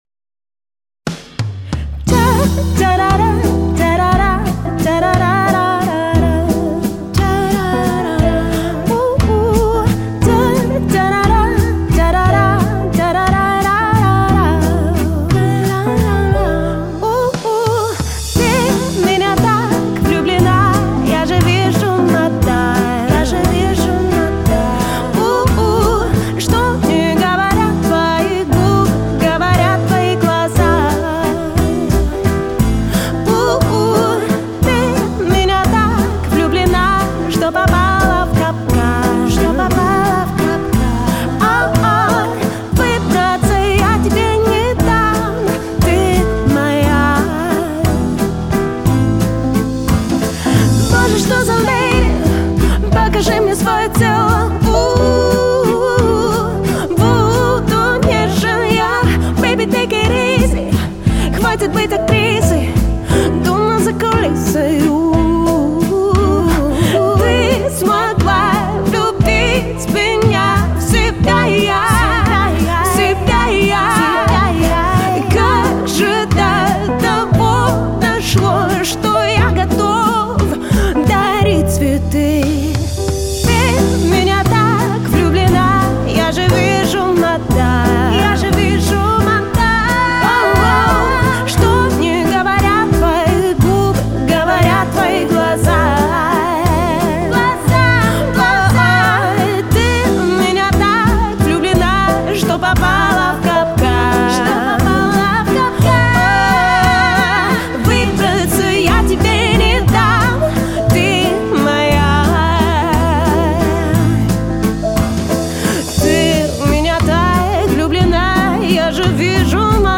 Trainingsmusik
The Viennese Waltz Mix 2.mp3